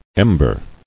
[em·ber]